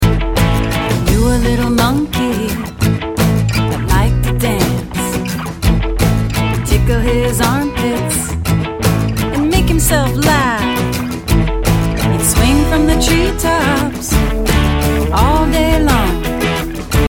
Children's Animal Song Lyrics and Sound Clip